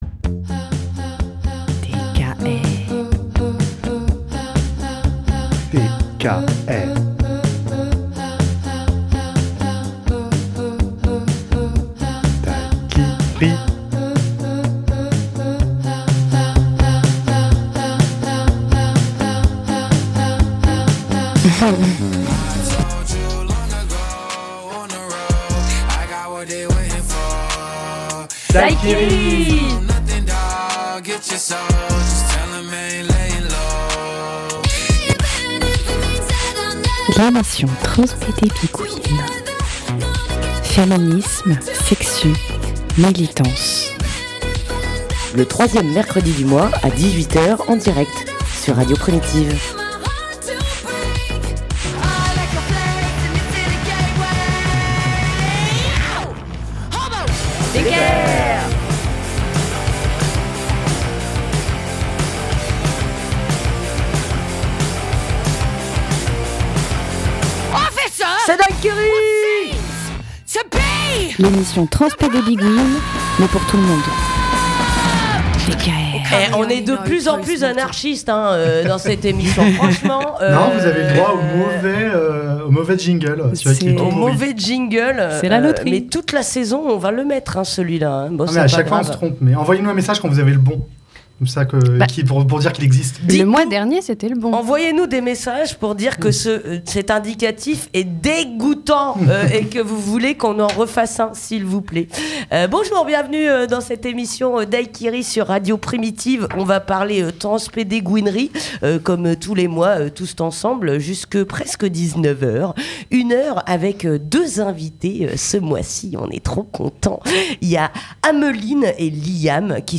On reçoit une partie de l'équipe organisatrice du Cabaret Queeriosity qui se tiendra le 7 mai au Temps des Cerises.